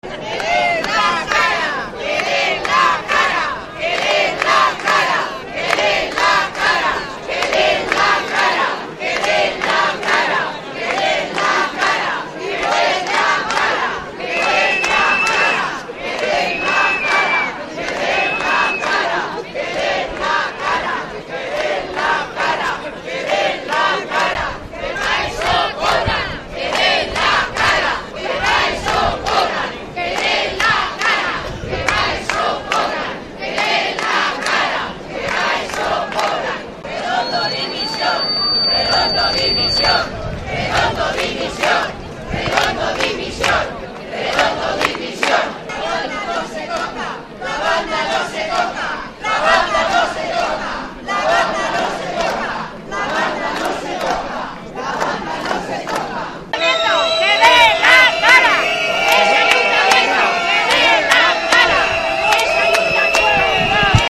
«Queremos a la Banda Municipal», «Redondo dimisión», «Redondo, dónde estás», «queremos información»  y «que den la cara, para eso cobran» fueron las consignas coreadas por las numerosas personas congregadas debajo del Ayuntamiento, en la Plaza de la Paz.
CONSIGNAS-Y-GRITOS_.mp3